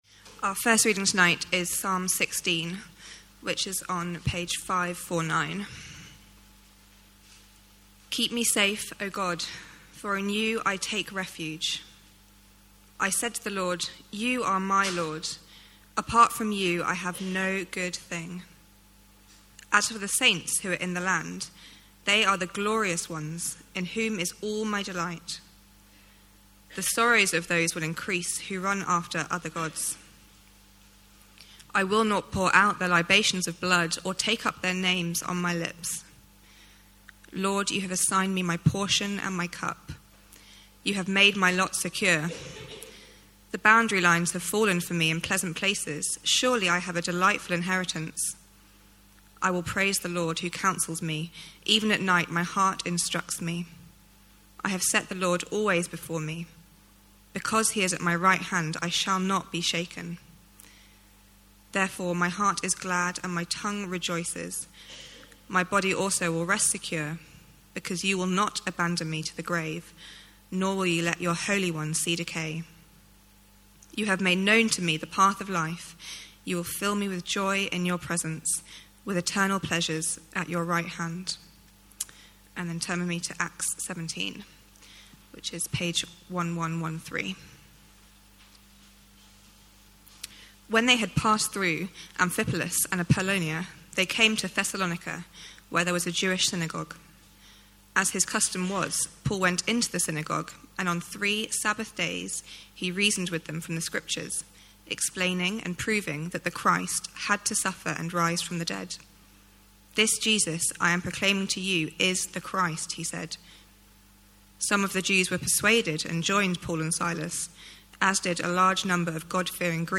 A sermon given at Dundonald Church, London UK